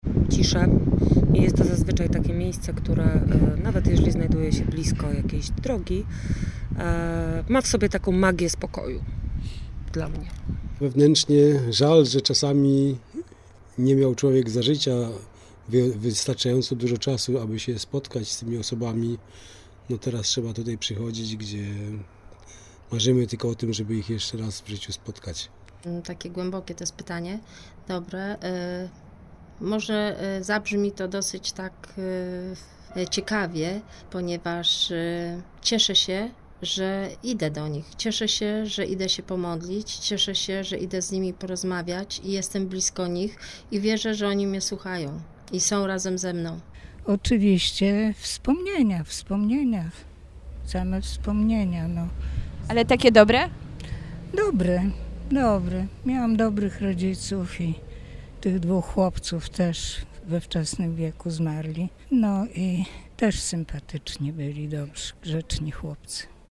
Jak wspomina jedna z rozmówczyń, cmentarz jest dla niej miejscem modlitwy i refleksji.